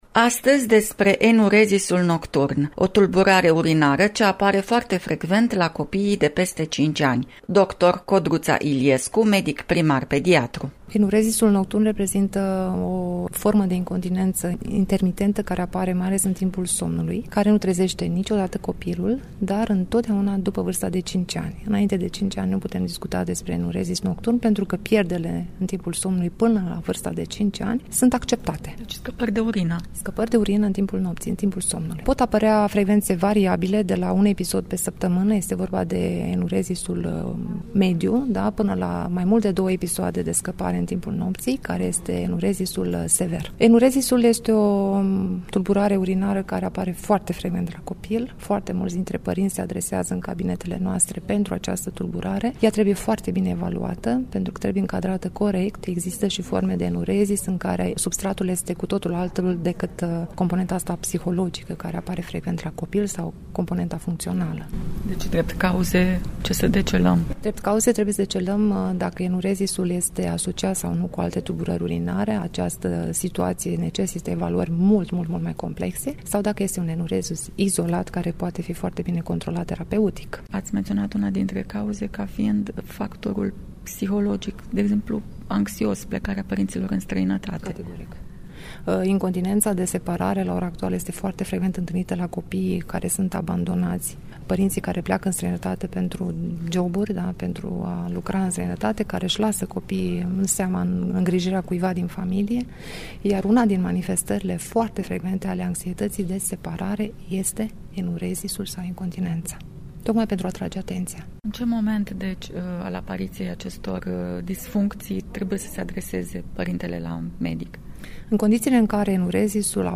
medic primar pediatru